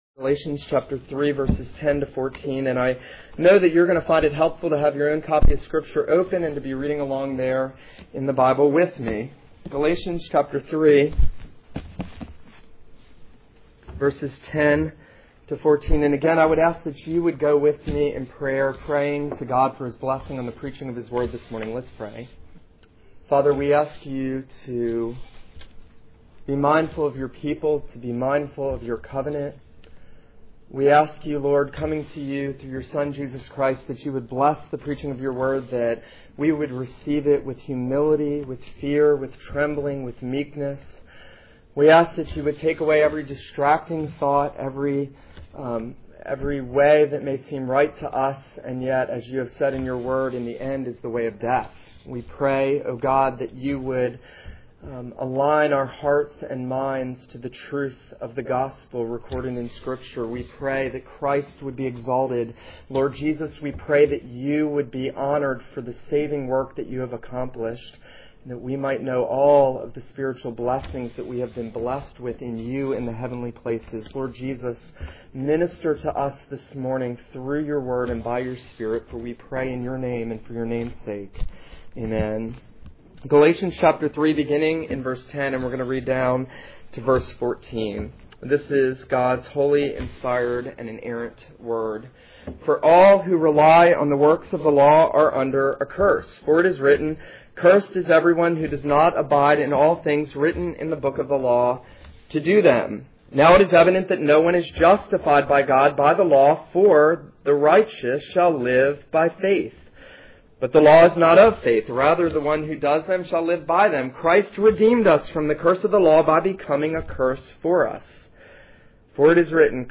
This is a sermon on Galatians 3:10-14.